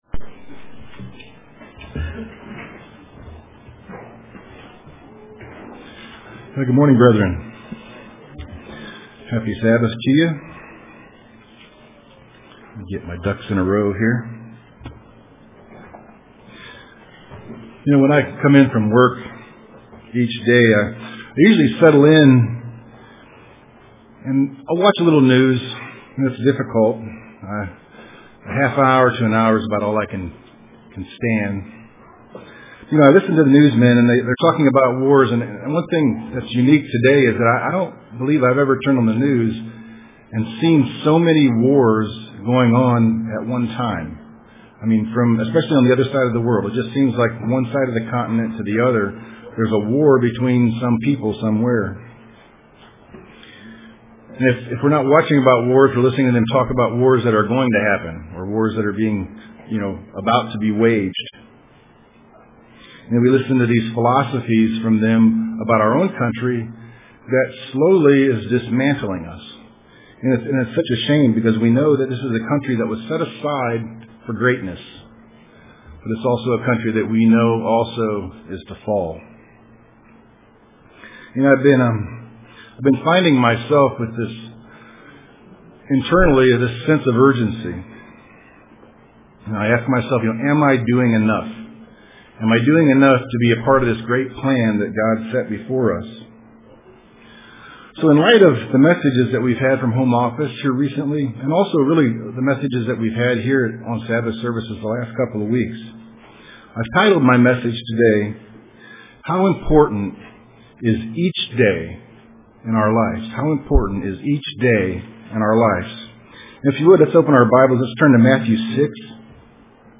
Print How Important is Each Day in Our Lives UCG Sermon Studying the bible?